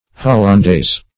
Hollandaise sauce \Hol`lan*daise" sauce\, or Hollandaise
hollandaise.mp3